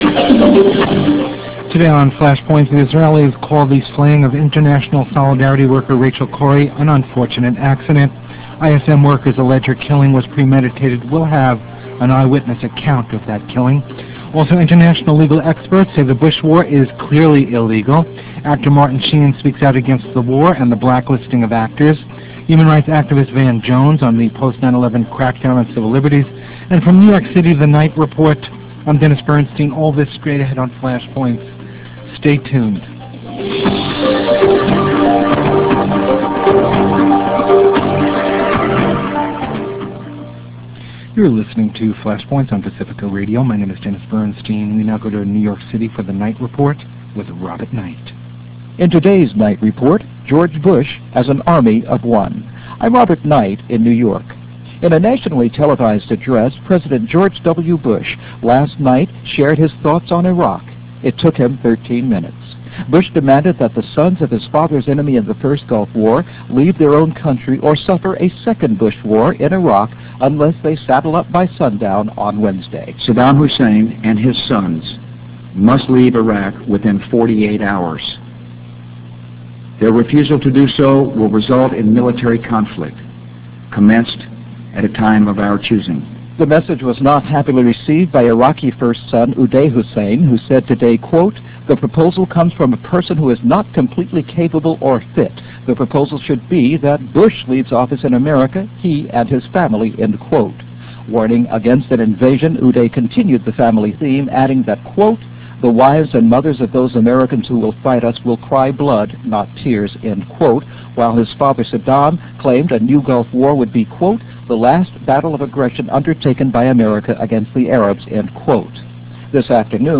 -Also, International Legal Experts Say the Bush War is Clearly Illegal -Actor Martin Sheen Speaks Out Against the War and the Blacklisting of Actors.